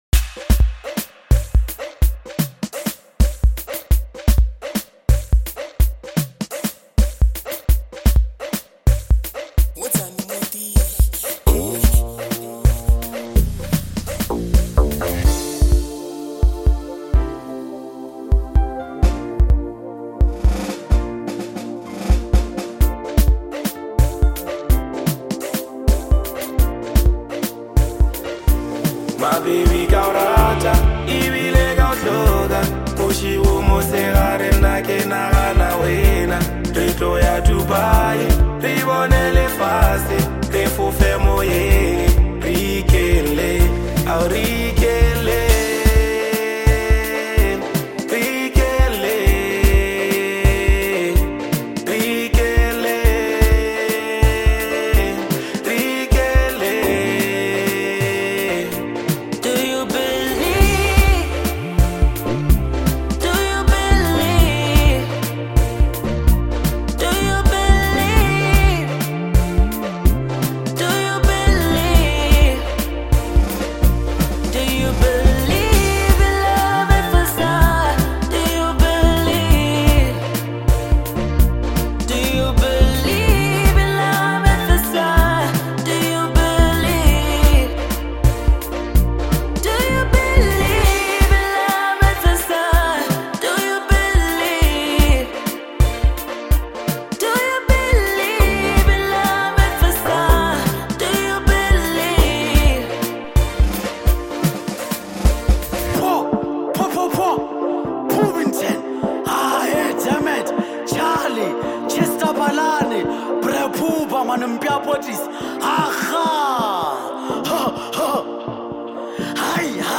is a deeply motivational song
It delivers motivation without losing rhythm.